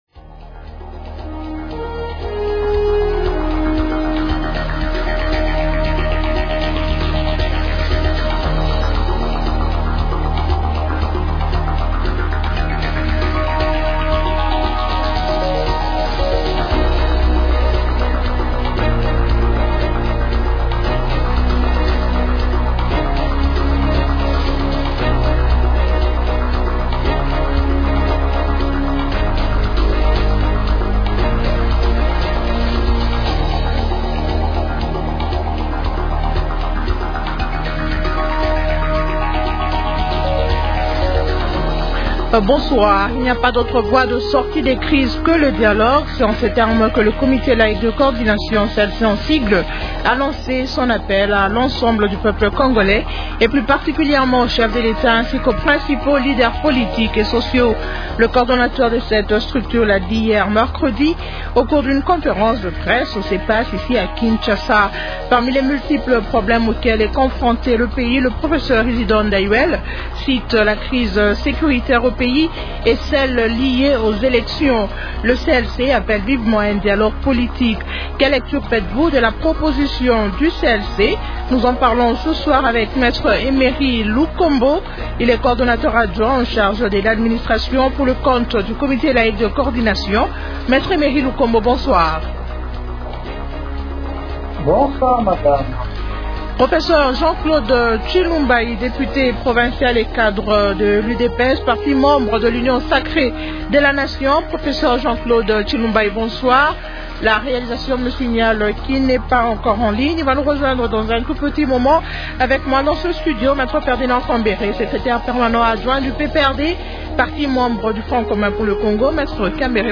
-Quelle lecture faites-vous de la proposition du CLC ? Invités :
-Jean-Claude Tshilumbayi, député provincial et cadre de l’UDPS, parti membre de l’Union sacrée de la nation.